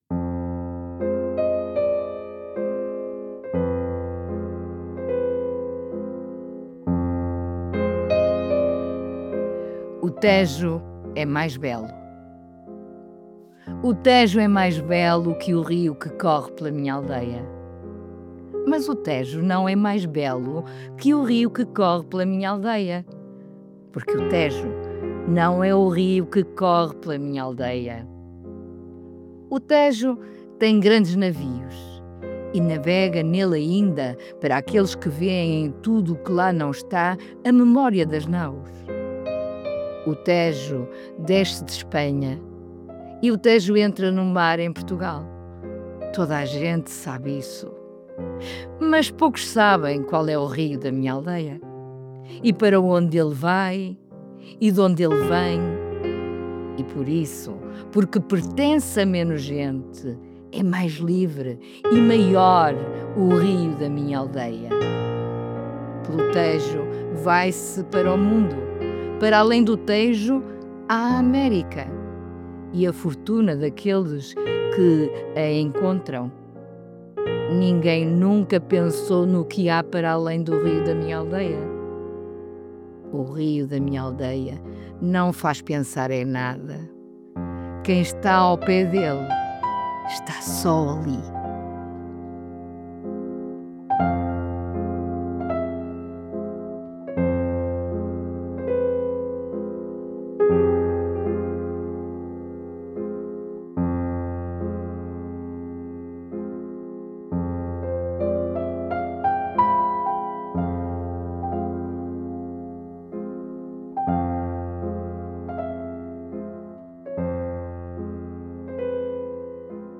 Música: Gnossienne No. 1